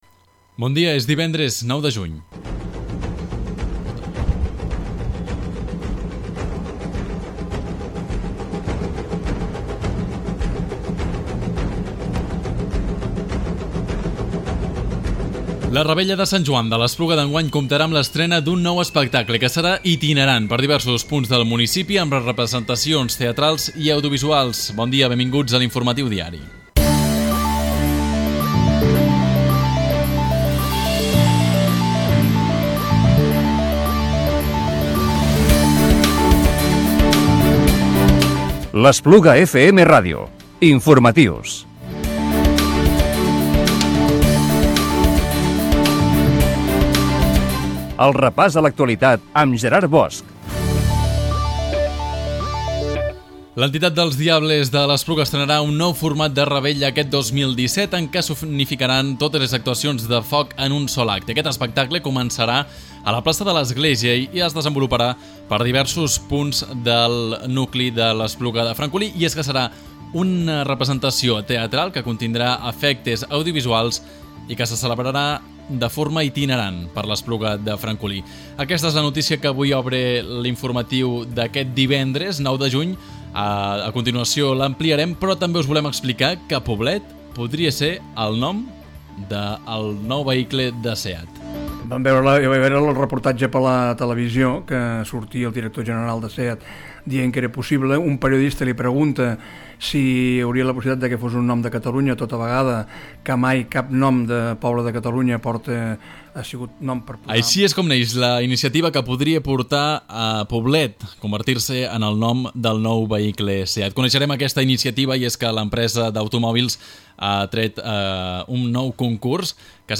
Informatiu diari del divendres 9 de juny del 2017